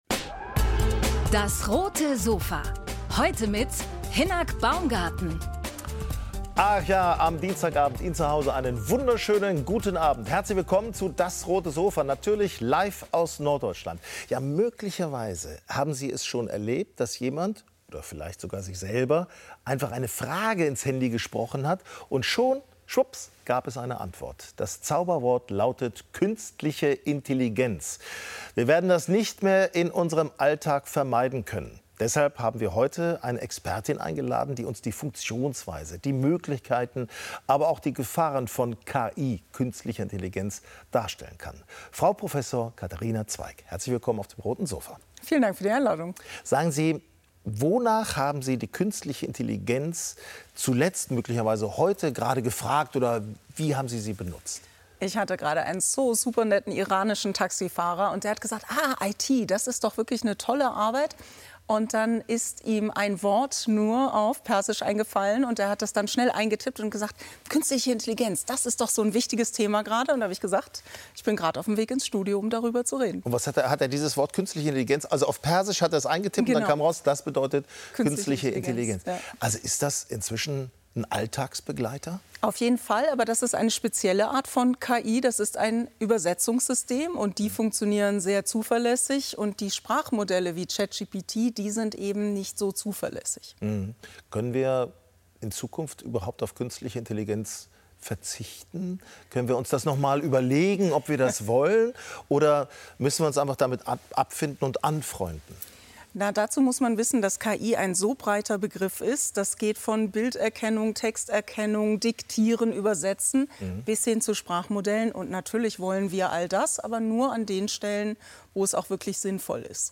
Ein Gespräch über Chancen und Risiken einer neuen Technik, auf der eine große Hoffnung der Menschheit ruht: Künstliche Intelligenz.